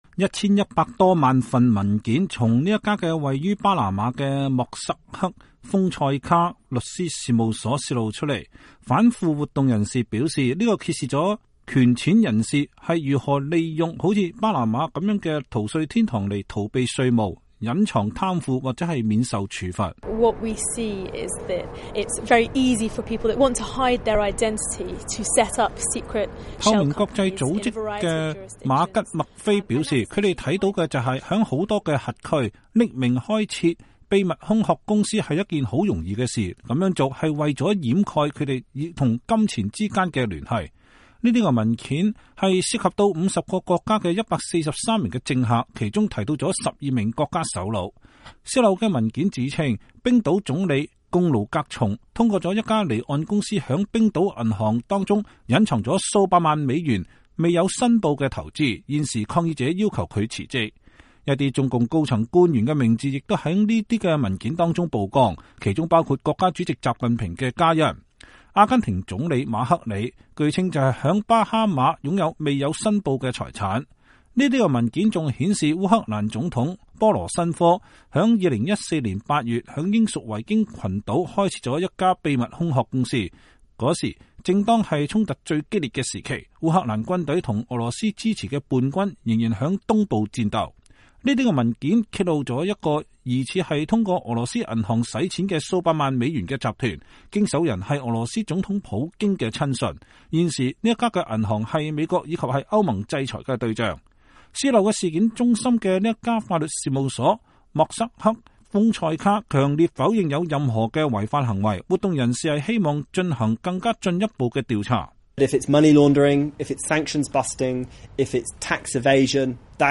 2016-04-05 美國之音視頻新聞: 泄漏文件揭露隱藏財富和貪腐 許多高官富商受牽連